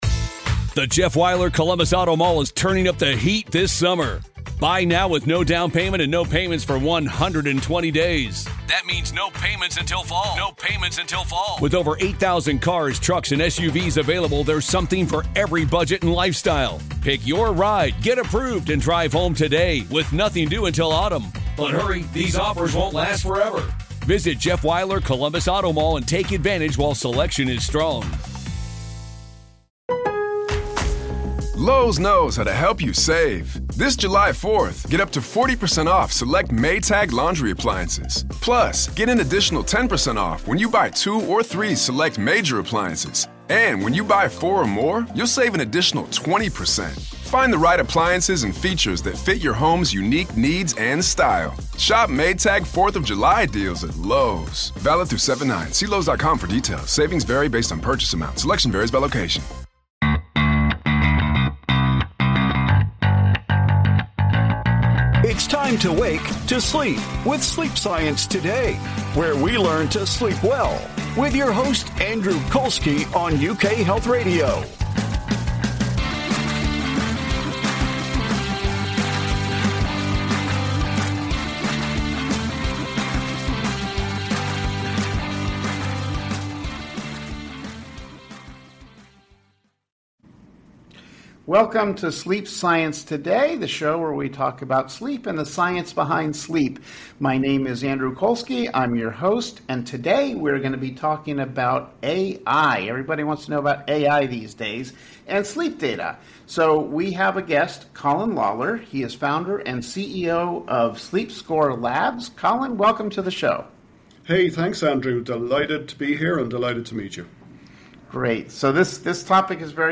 You will hear from renowned sleep experts as they share the latest information about how to sleep better with science. Through compelling interviews covering the issues you care about most